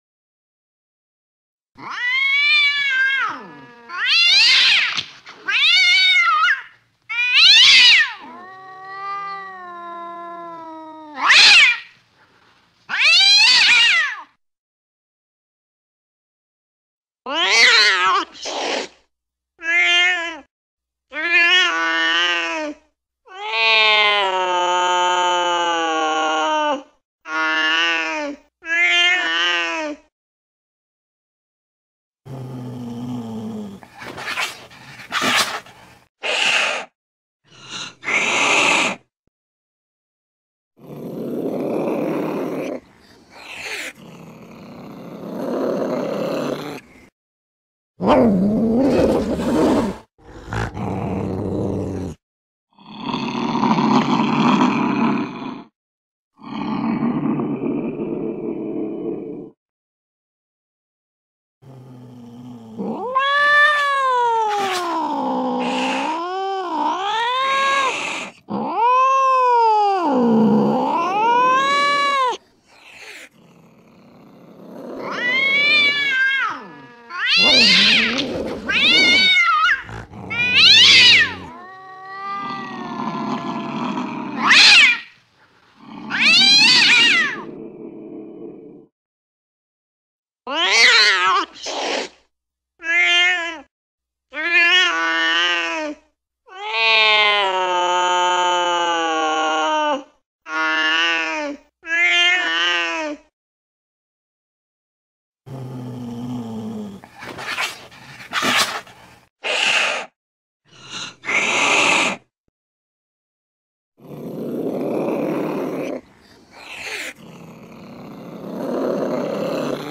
เสียงแมวโกรธ
เสียงแมวตัวผู้ เสียงแมวร้องขู่
หมวดหมู่: เสียงสัตว์เลี้ยง
tieng-meo-keu-gian-du-tuc-gian-th-www_tiengdong_com.mp3